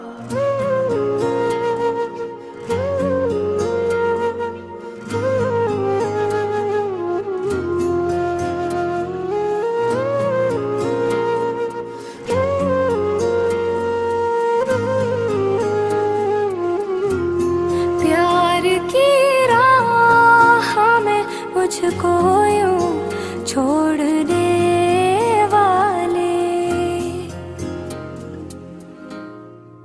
emotional instrumental cover
Category: Instrumental Ringtones